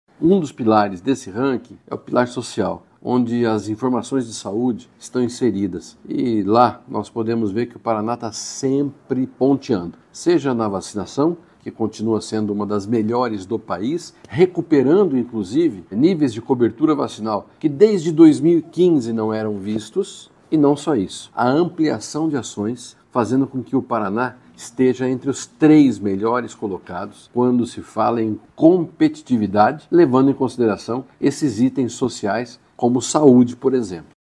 Sonora do secretário da Saúde, Beto Preto, sobre os resultados do Paraná no Ranking de Competitividade dos Estados